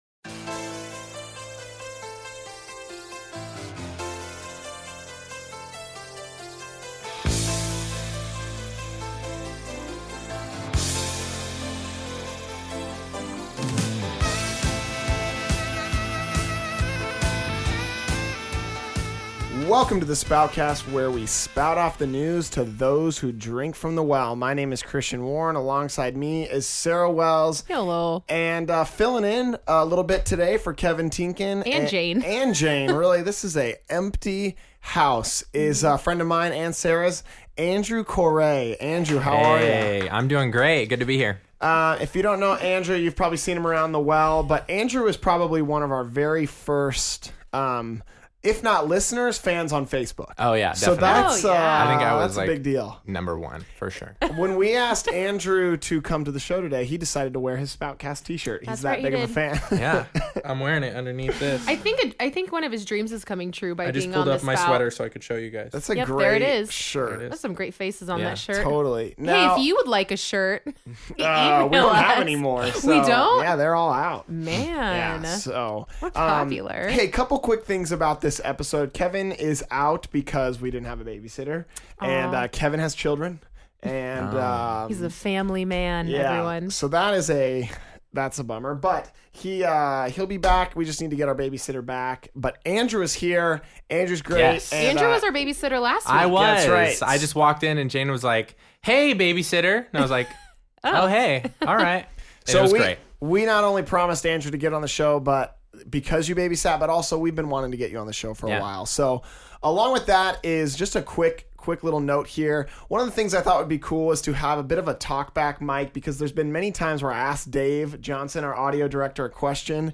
They talk about living in the intern house, and about his experience interning in the Worship department. The Spoutcast Crew also discusses their thoughts on the sermon, and how each of them responded to the message.